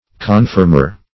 Search Result for " confirmer" : The Collaborative International Dictionary of English v.0.48: Confirmer \Con*firm"er\, n. One who, or that which, confirms, establishes, or ratifies; one who corroborates.